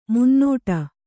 ♪ munnōṭa